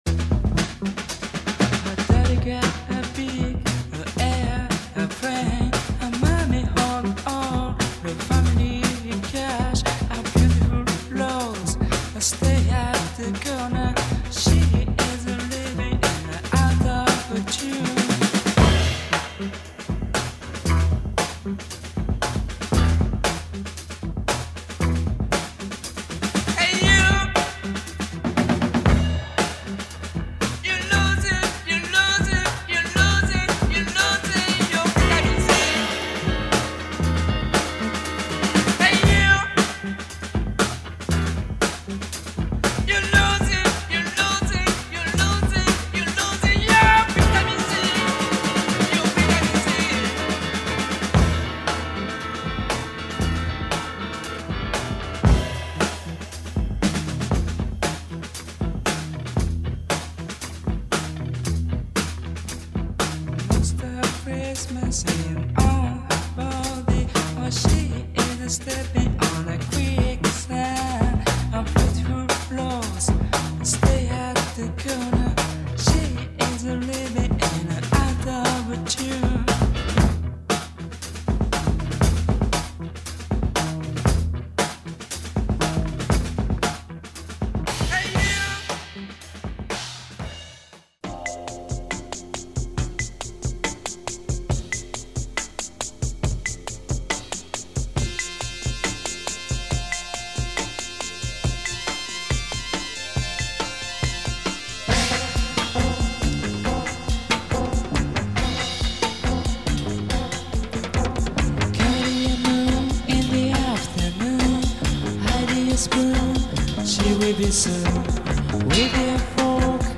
Krautfunk at its best here !